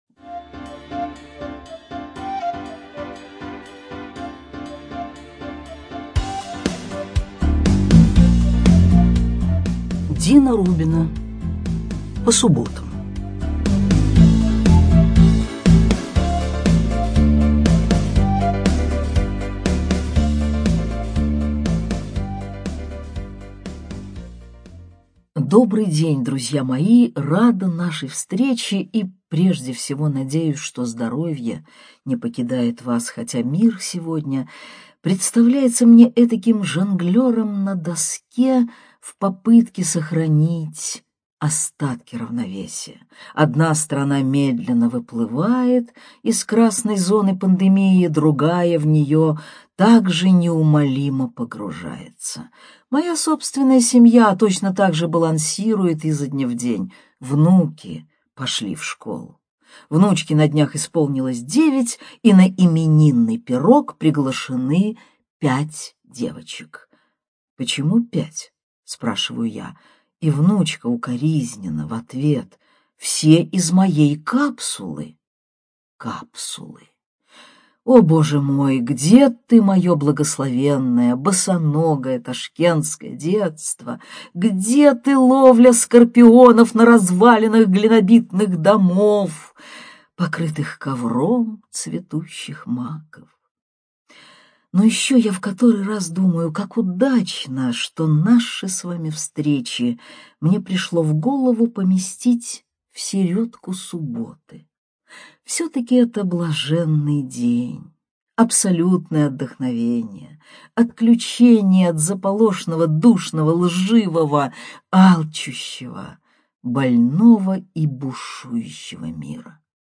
ЧитаетАвтор
Студия звукозаписивимбо